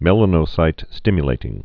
(mĕlə-nō-sītstĭmyə-lātĭng, mə-lănə-)